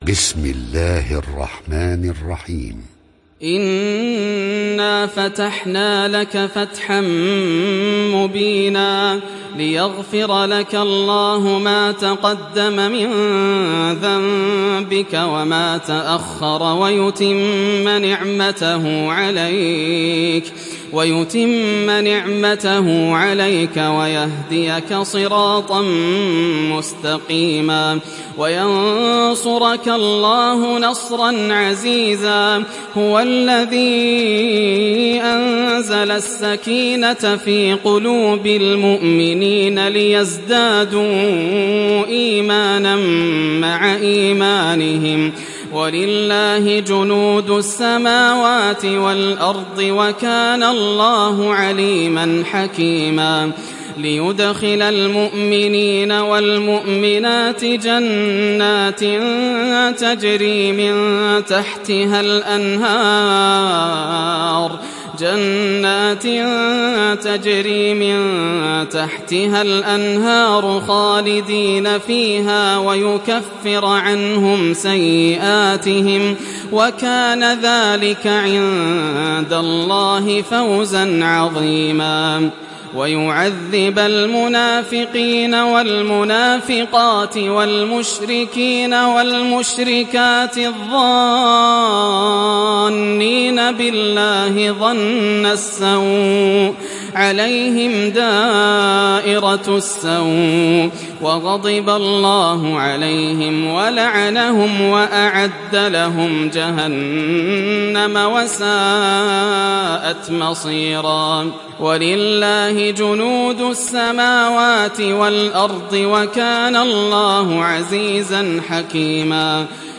تحميل سورة الفتح mp3 بصوت ياسر الدوسري برواية حفص عن عاصم, تحميل استماع القرآن الكريم على الجوال mp3 كاملا بروابط مباشرة وسريعة